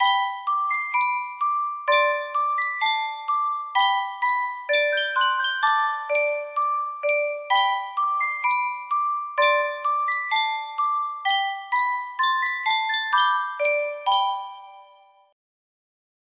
Sankyo Music Box - Schubert's Lullaby S music box melody
Full range 60